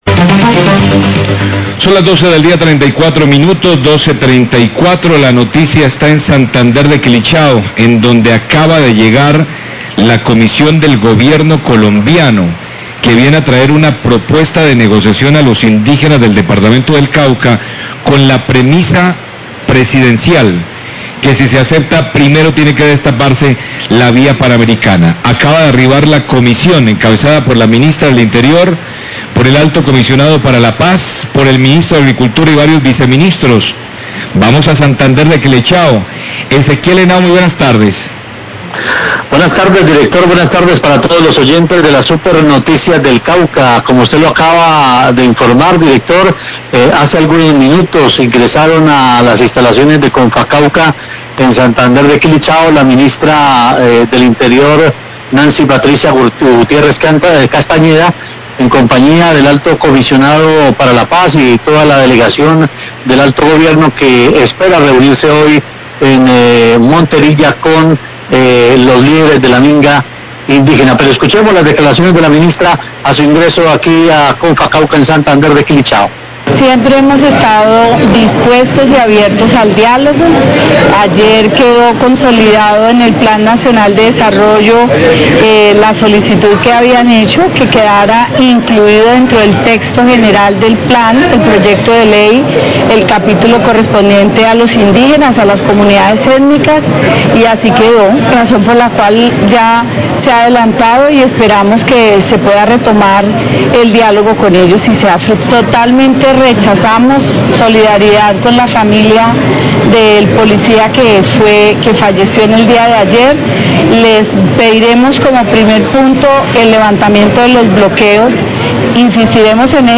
Radio
La Ministra del Interior; Nancy Patricia Gutierrez, y el Alto Comisionado para la Paz, miguel Ceballos, hablan sobre la propuesta que traen para la minga indígena que, si es aprobada, exige como primer punto el fin de los bloqueos a al via Panamericana.